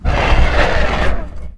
c_horisath_atk3.wav